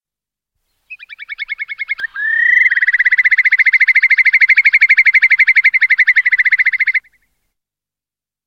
Courlis corlieu
Numenius phaeopus